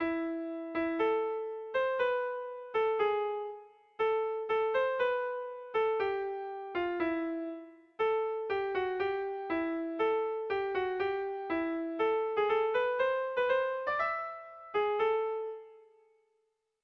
Erromantzea
ABD